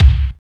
25.03 KICK.wav